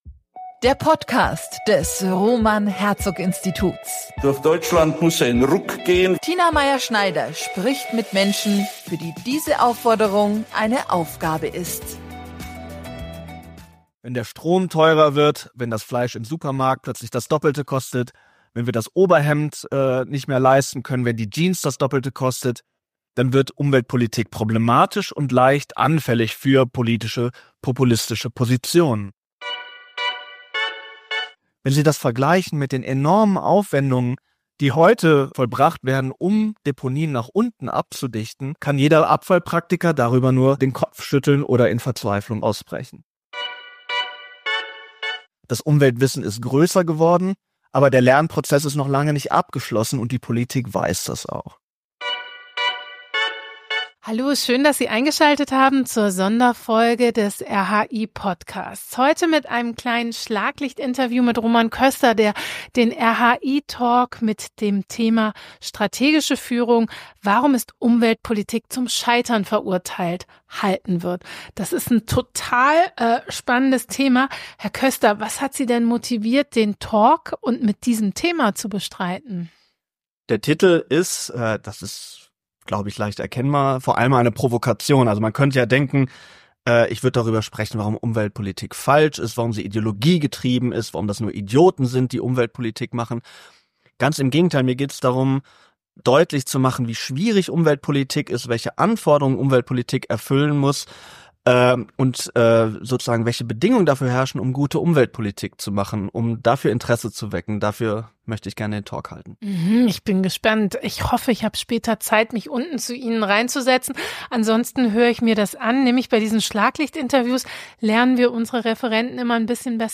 Was macht gute strategische Führung in Politik und Gesellschaft aus? Dieser Frage haben wir uns gemeinsam mit neun Expertinnen und Experten im Rahmen des RHI-Symposiums am 26.11.2024 gewidmet.